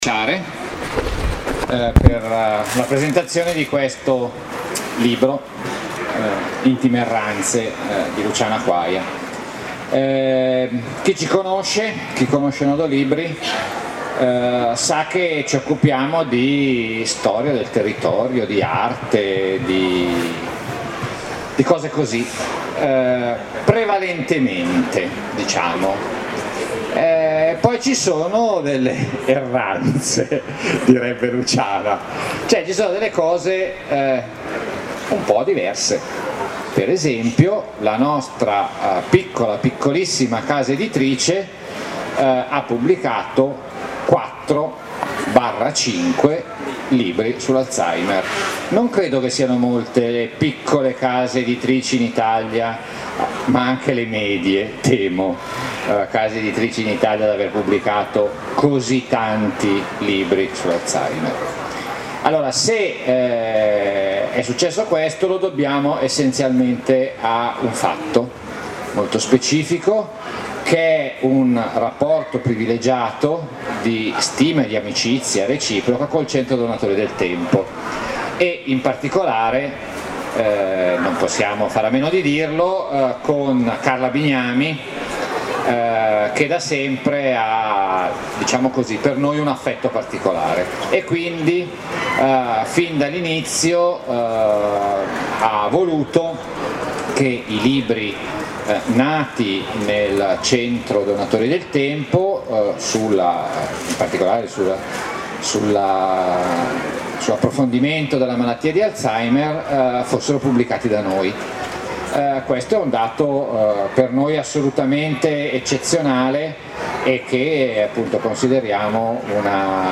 Il video documenta la serata di conversazione che si è svolta in occasione della Fiera del Libro di Como, giunta al suo sessantesimo anno, e si sviluppa in modo davvero empatico, attorno a questi punti di attenzione:
I partecipanti erano nel tendone di Piazza Cavour di Como la sera del 28 agosto 2012.